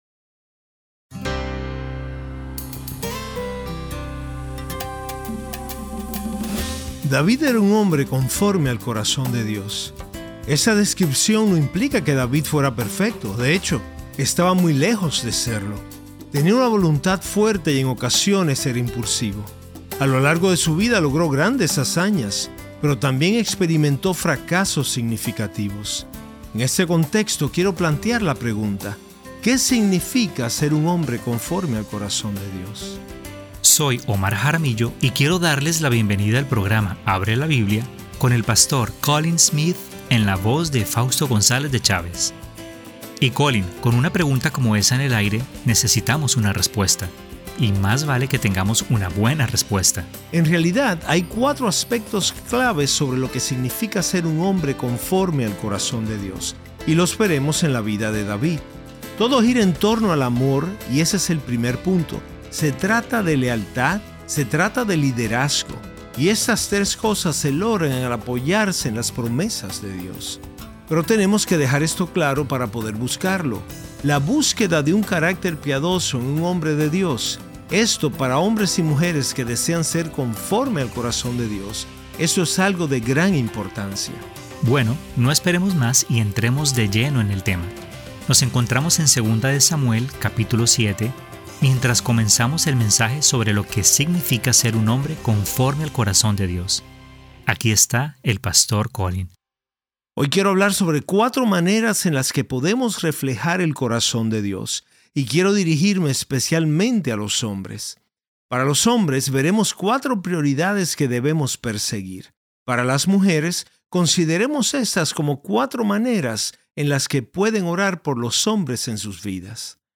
Un hombre conforme al corazón de Dios, Parte 1 - Sermón - Abre la Biblia